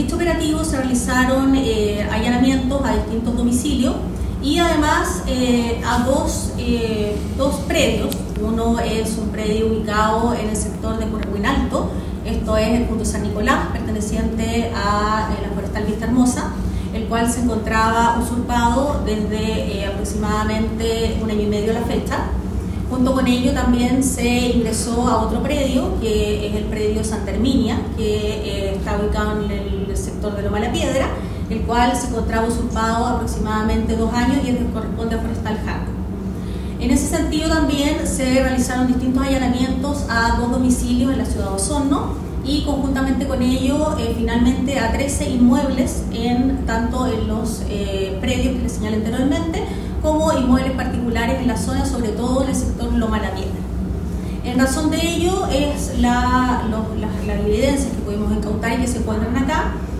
Por su parte, la Fiscal Jefe de Osorno, María Angélica de Miguel, detalló que durante el operativo se realizaron allanamientos en varios domicilios y predios ubicados en los sectores de Purrehuín Alto y Loma de la Piedra.